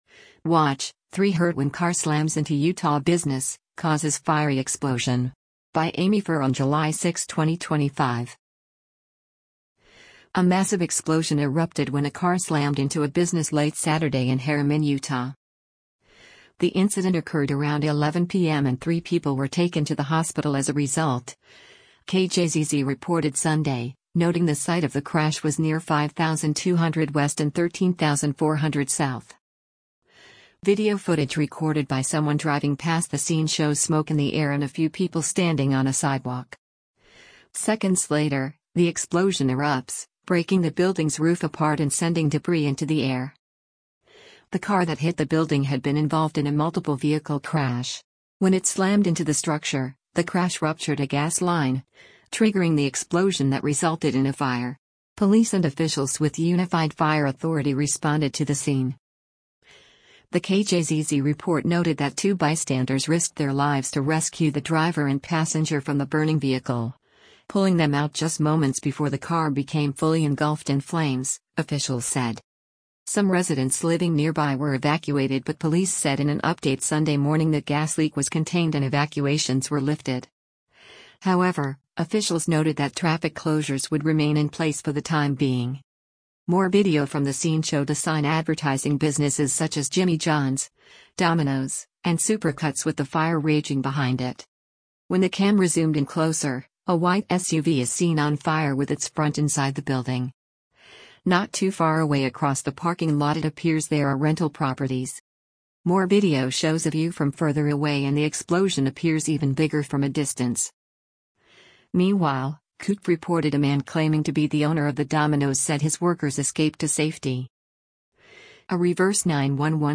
Video footage recorded by someone driving past the scene shows smoke in the air and a few people standing on a sidewalk. Seconds later, the explosion erupts, breaking the building’s roof apart and sending debris into the air: